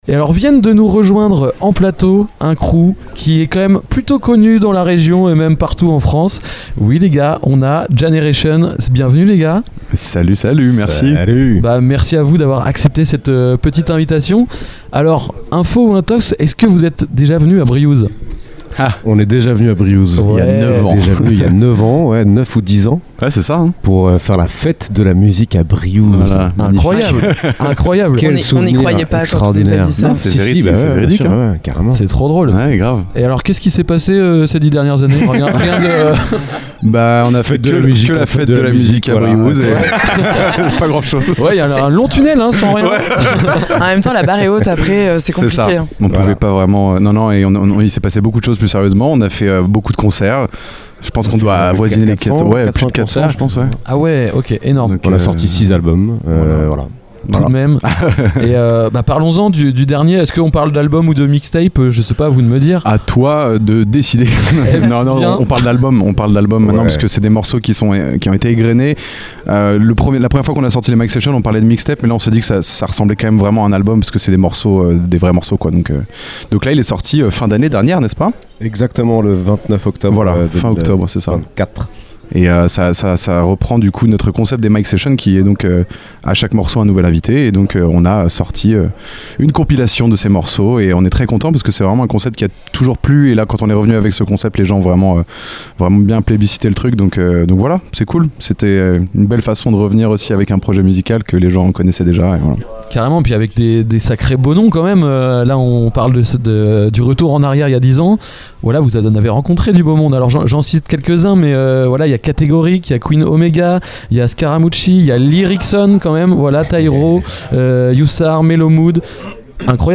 Jahneration du 19.07.2025 Dans cette interview réalisée dans le cadre de l’émission spéciale enregistrée au festival Art Sonic à Briouze, les radios de l’Amusicale — Ouest Track, Station B, PULSE, Kollectiv’, 666, Radio Sud Manche, Radio Coup de Foudre, Radar, Phénix, Radio Campus Rouen et TST Radio — sont parties à la rencontre des artistes qui font vibrer le festival. Dans cet épisode, nous recevons Jahneration, duo incontournable de la scène reggae française. Ils reviennent sur leur parcours, leur énergie scénique et leur lien fort avec le public, porté par des textes engagés et des rythmes fédérateurs. Une interview chaleureuse et rythmée qui capture toute la vibe positive de Jahneration et l’ambiance unique d’Art Sonic. festival La Musicale Art Sonic